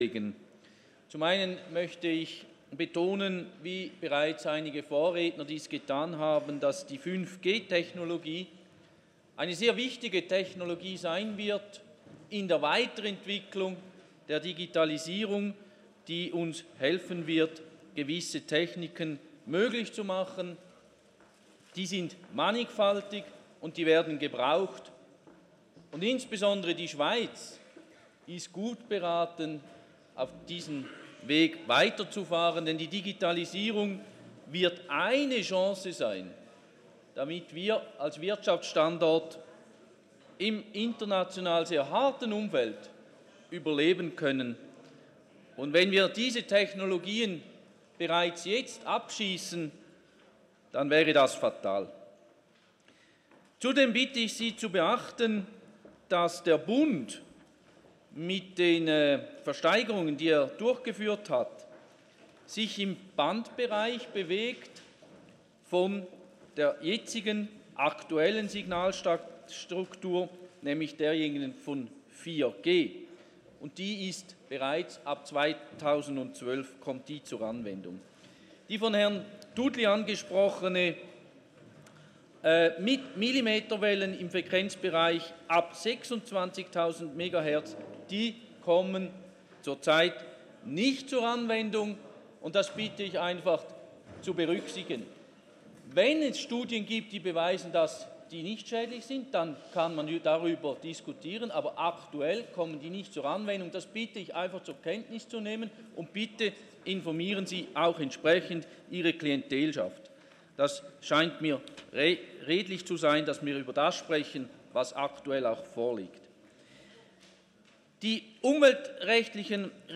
12.6.2019Wortmeldung
Regierungsrat:
Session des Kantonsrates vom 11. bis 13. Juni 2019